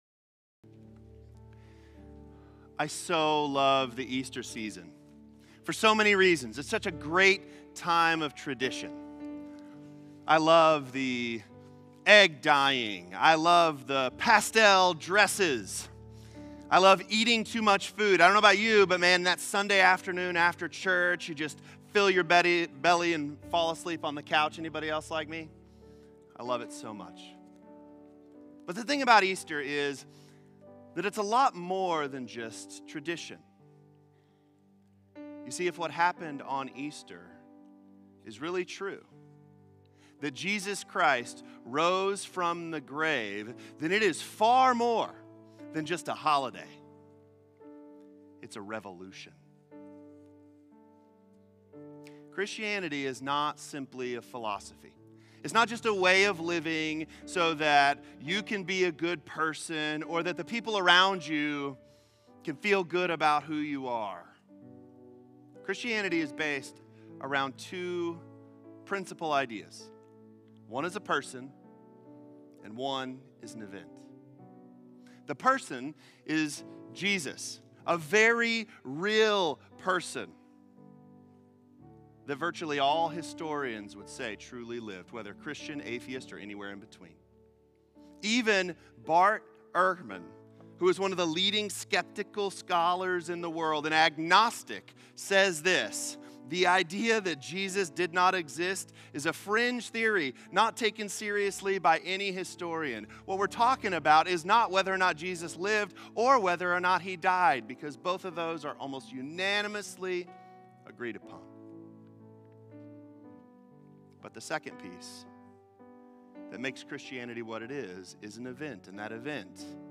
This-Changes-Everything-Sermon-4.20.25.m4a